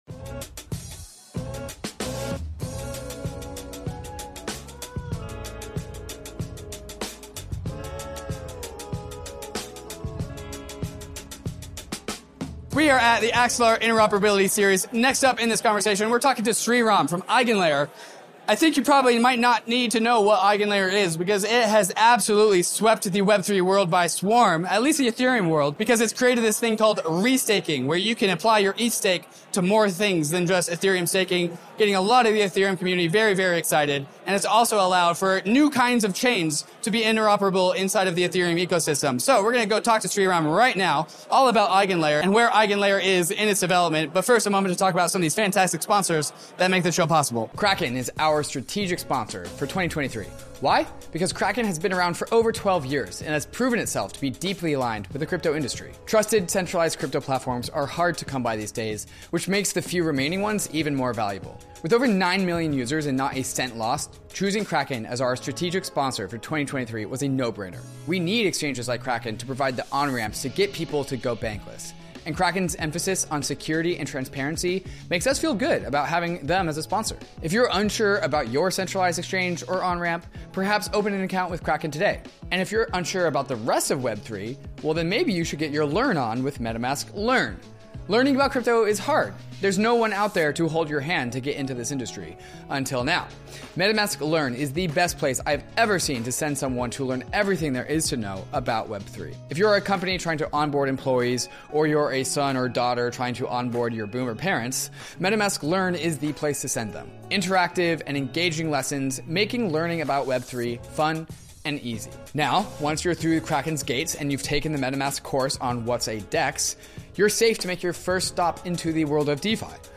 ETHDenver 2023 Interview #6
Sometimes, the frontier is at a crypto conference.